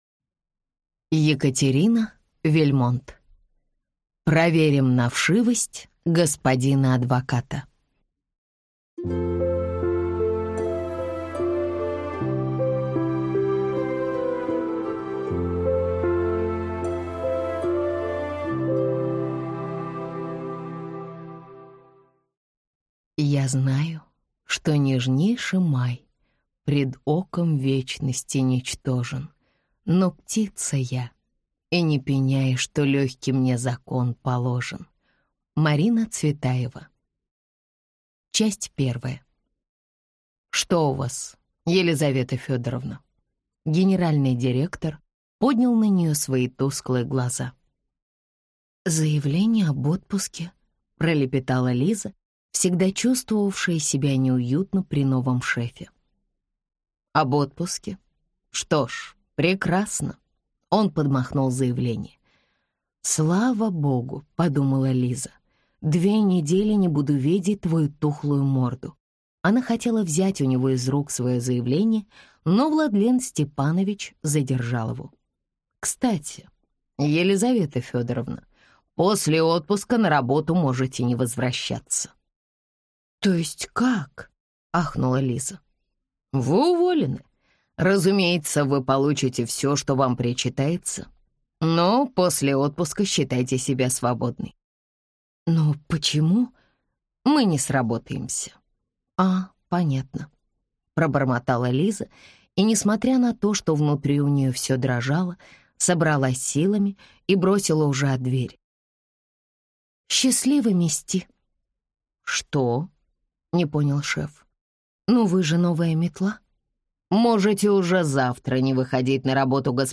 Аудиокнига Проверим на вшивость господина адвоката | Библиотека аудиокниг